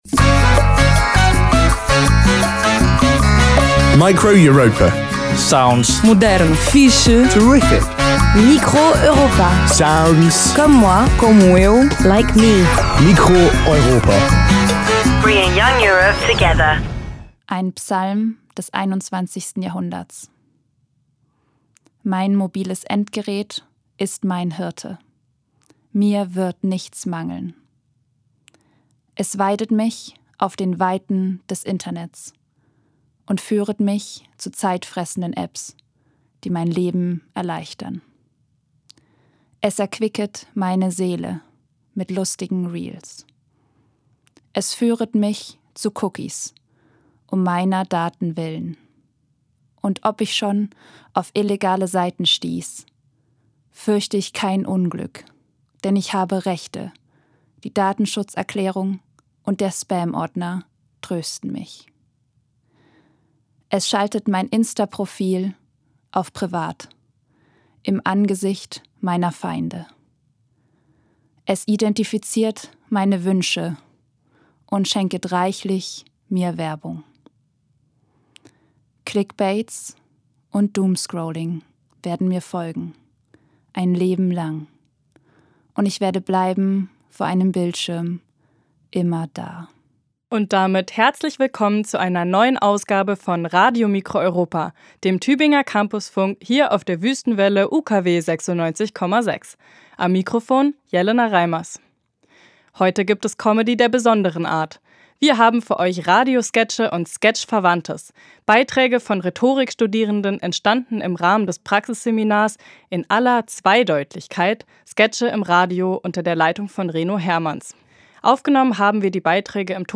Aufgenommen haben wir die Beiträge im Tonstudio des Brechtbaus Tübingen im Sommersemster 2024.
Live-Aufzeichnung, geschnitten